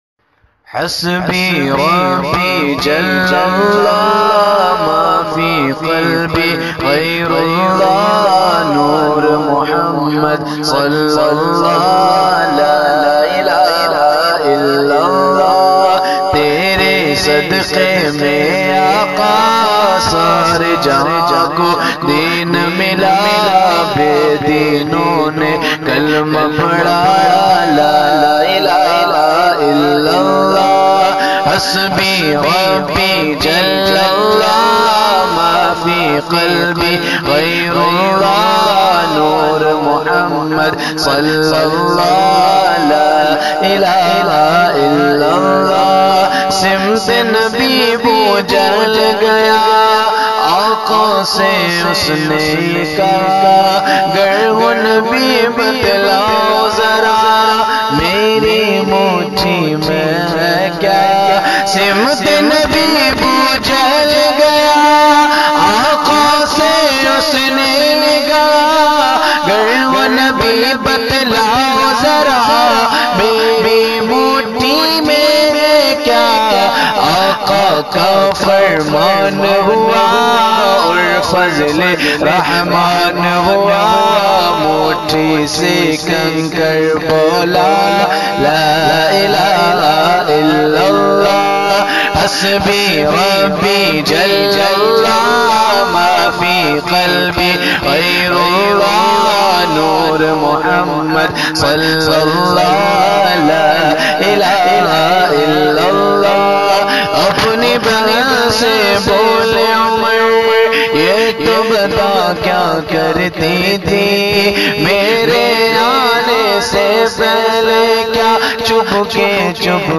New Naat